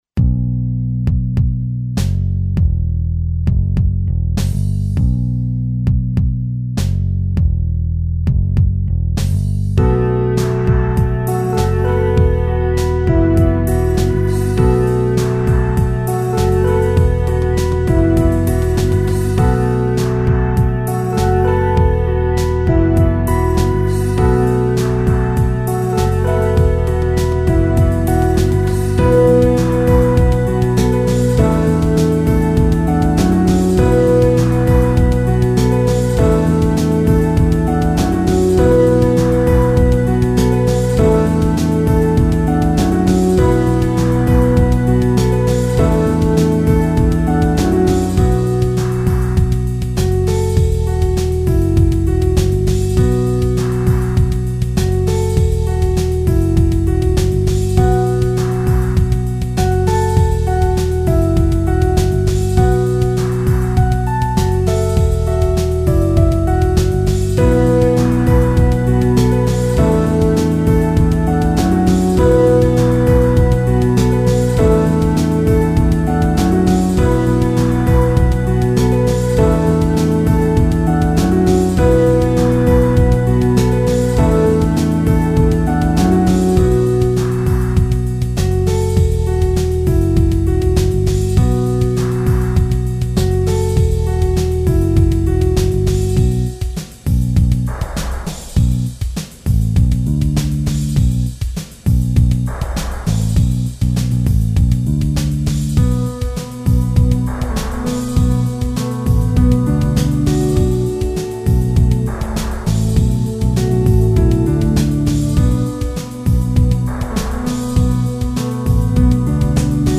Funky Rocker